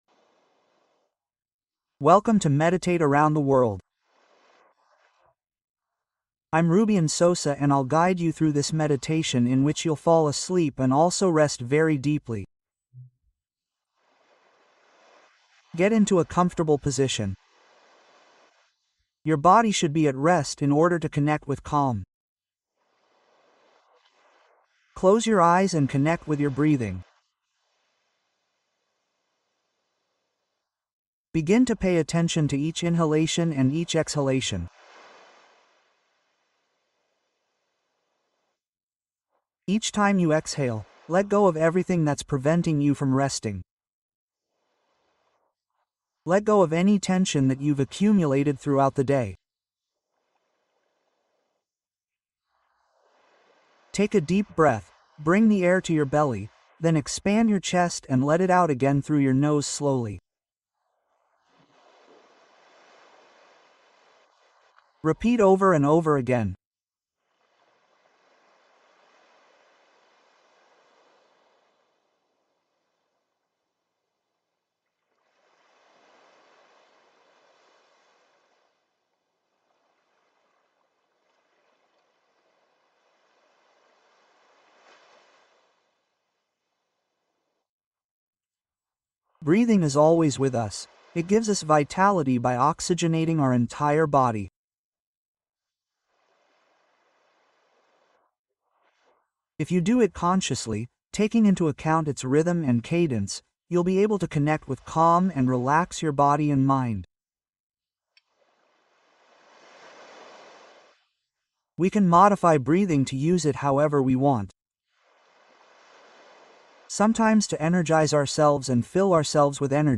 Sueño Profundo en 15 Minutos: Meditación Suave para Relajar Cuerpo y Mente